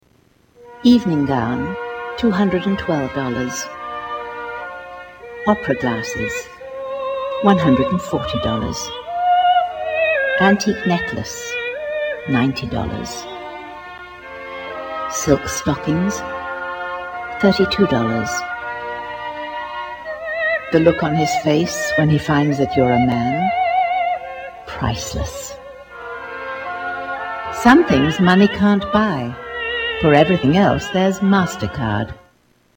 Tags: Media MasterCard Advertisement Commercial MasterCard Clips